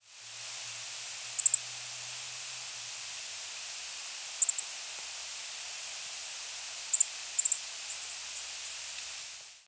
Dark-eyed Junco diurnal flight calls
Perched bird.
Bird perched, then in flight giving "tk" calls.